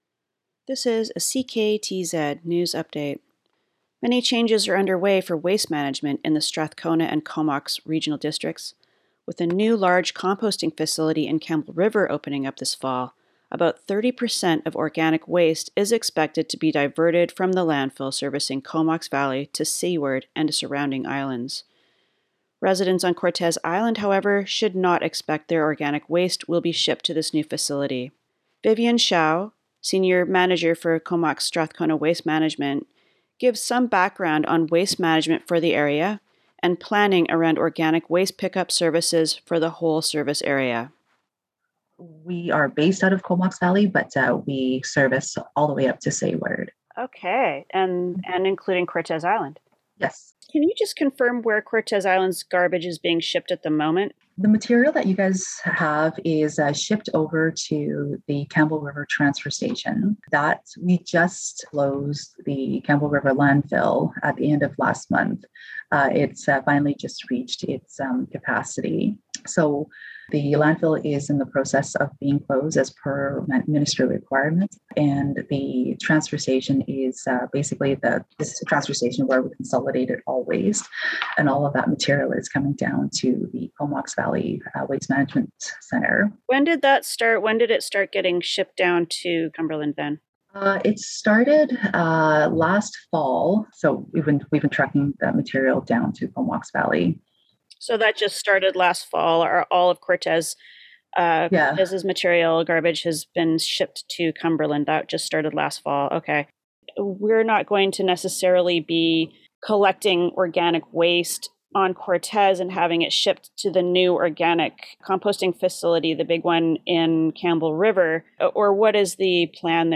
CKTZ-News-Composting-facility.mp3